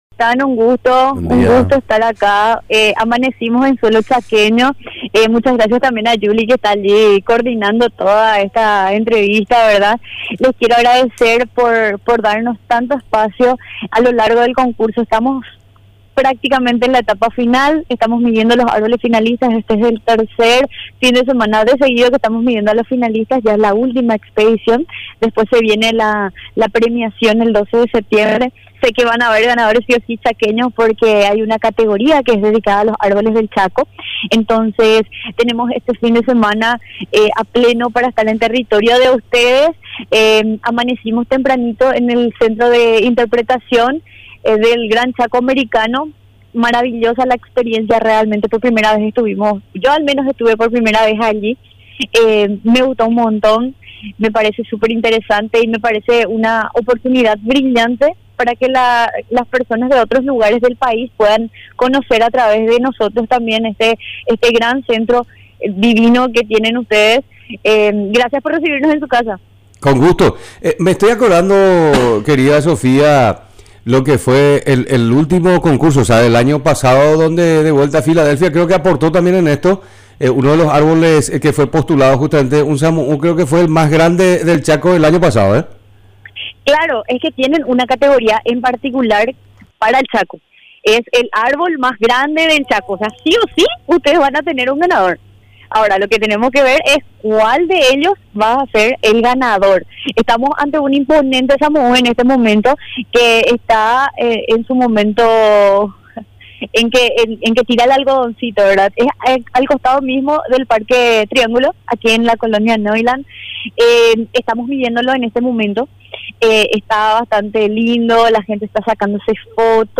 Entrevistas / Matinal 610 Expedición de los colosos en el chaco central Aug 30 2024 | 00:13:33 1x 00:00 / 00:13:33 Subscribe Share RSS Feed Share Link Embed